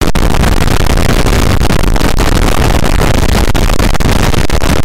Sounds of a microphone download and listen online
• Category: Microphone